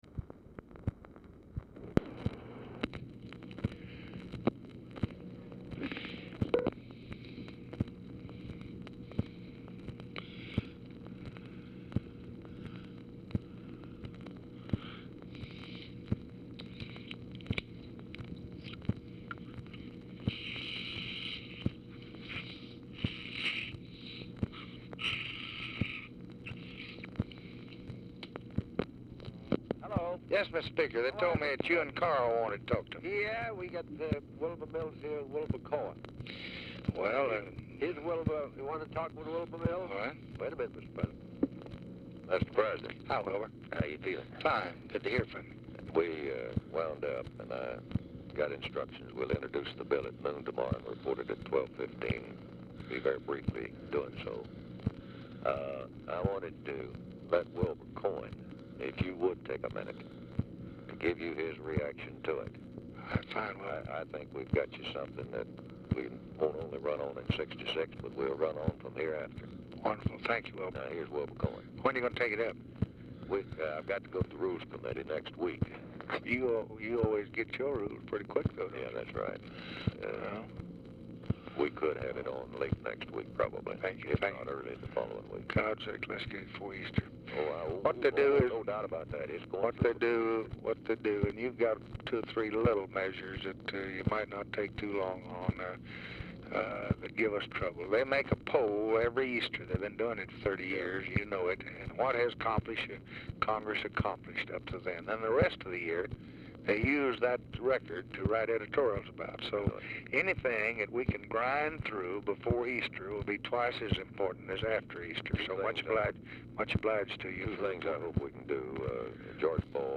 Telephone conversation # 7141, sound recording, LBJ and JOHN MCCORMACK, 3/23/1965, 4:54PM | Discover LBJ
Format Dictation belt
Specific Item Type Telephone conversation